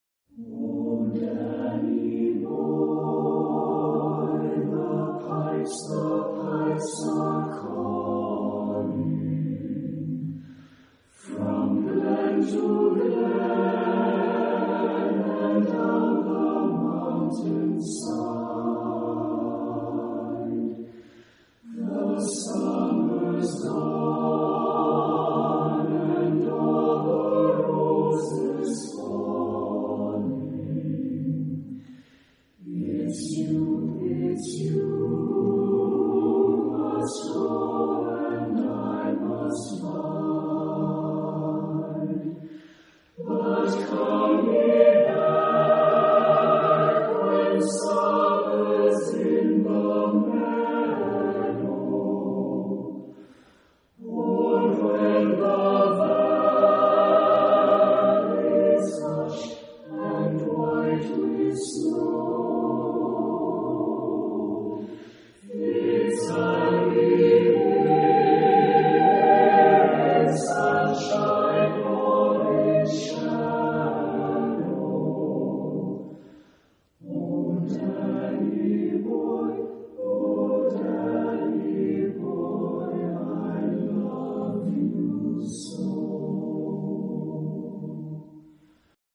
SATB (4 voix mixtes) ; Partition complète.